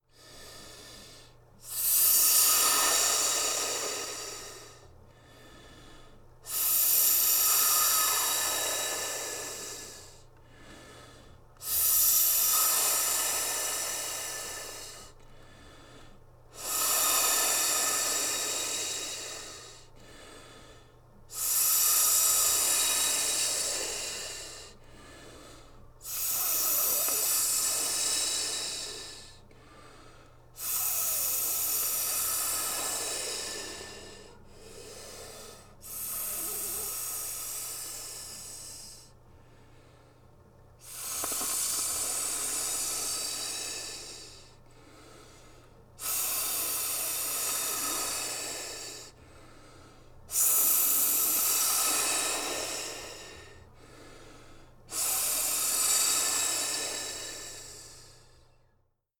Blue-Snowball Blue-brand cartoon snake snoring sound effect free sound royalty free Movies & TV